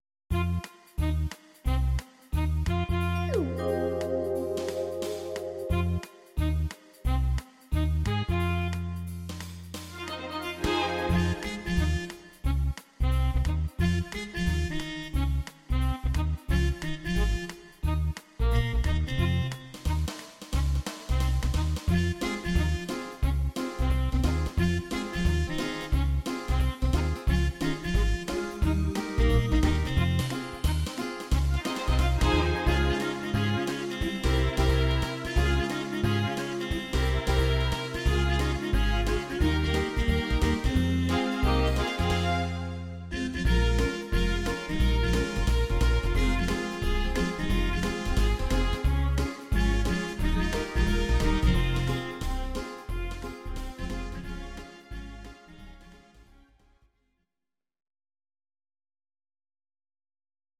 Audio Recordings based on Midi-files
Our Suggestions, Pop, 1980s